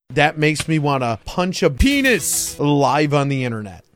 Play, download and share bamPunch original sound button!!!!
bampunch.mp3